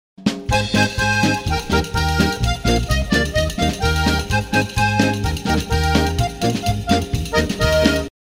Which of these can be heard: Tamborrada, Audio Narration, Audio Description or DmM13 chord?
Tamborrada